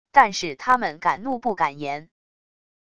但是他们敢怒不敢言wav音频生成系统WAV Audio Player